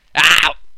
Epic Noise - AOH 2
Category: Sound FX   Right: Personal